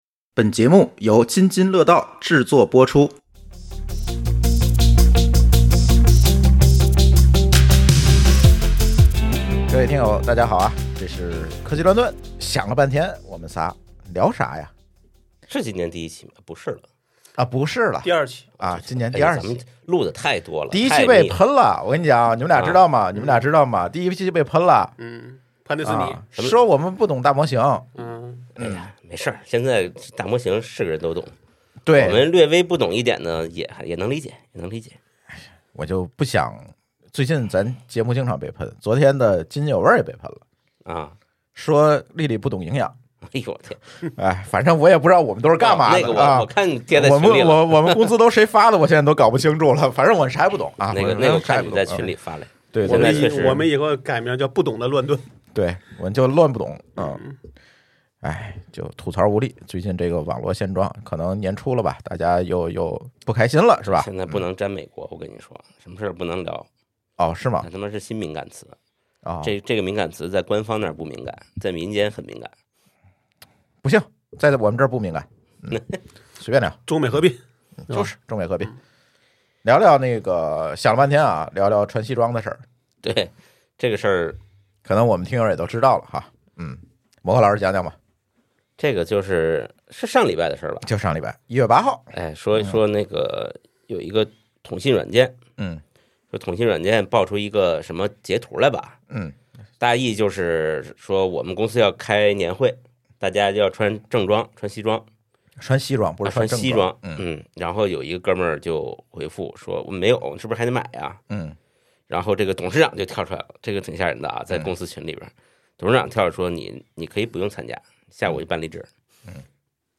【关于「科技乱炖」】 由多名资深从业者主持的科技点评播客，以实际工作中积累的经验为基础，结合实际，把近期科技热点变成犀利、独到、深刻的独家观点。